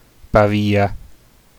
Pavia (UK: /ˈpɑːviə/ PAH-vee-ə,[3] US: /pəˈvə/ pə-VEE;[4] Italian: [paˈviːa]
It-Pavia.ogg.mp3